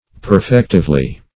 perfectively - definition of perfectively - synonyms, pronunciation, spelling from Free Dictionary Search Result for " perfectively" : The Collaborative International Dictionary of English v.0.48: Perfectively \Per*fec"tive*ly\, adv.